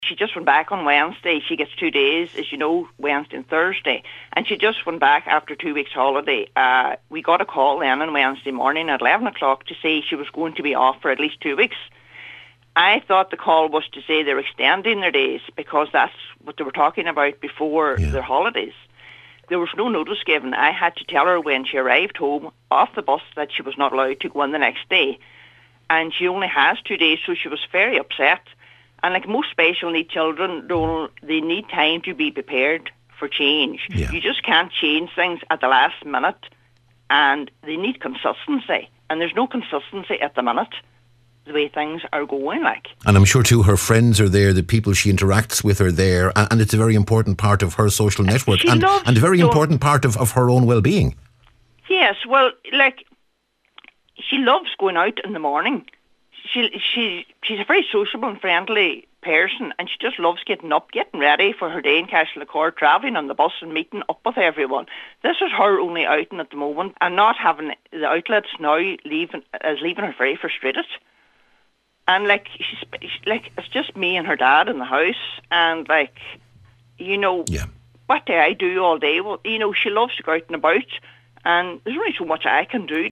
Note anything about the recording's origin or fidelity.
whose daughter attends the centre told today’s Nine til Noon Show that they have been left in a difficult position